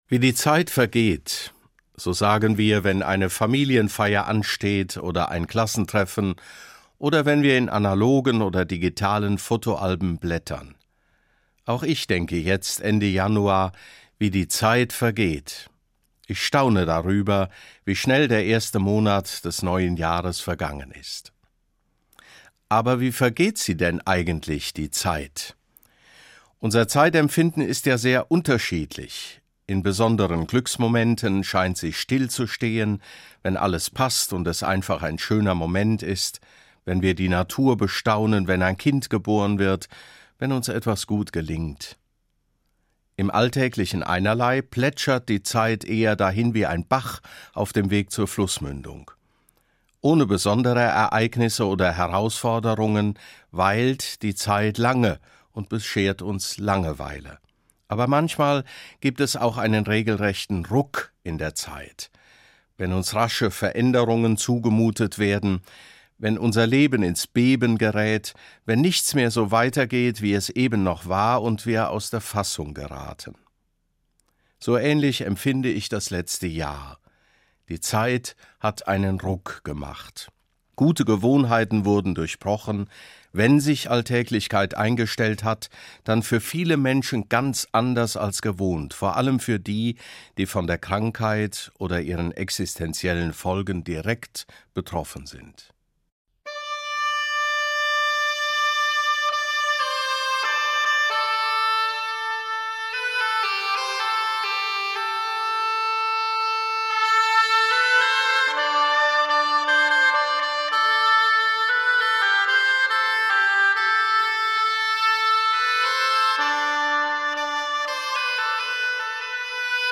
Eine Sendung von Dr. Georg Bätzing, Bischof von Limburg